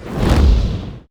fire1.wav